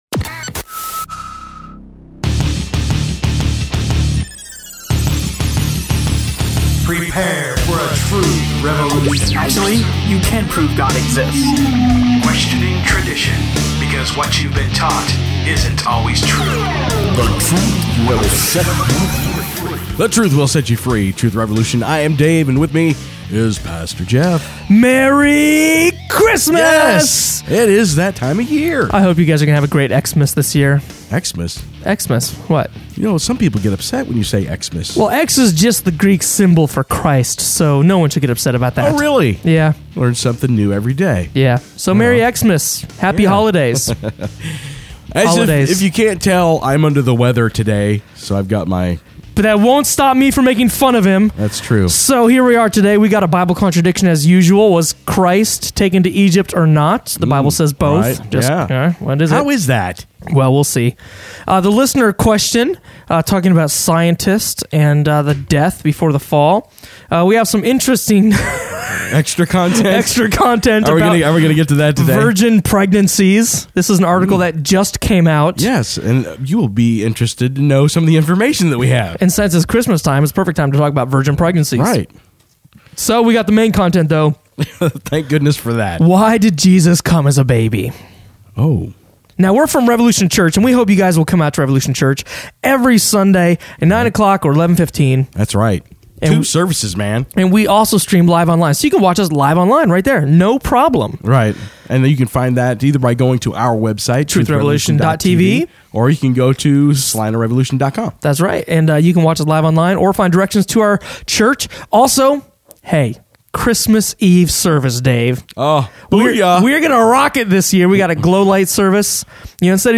Why Did Jesus Come as a Baby? – Truth Revolution Radio Show